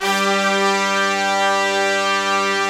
G3 POP BRA.wav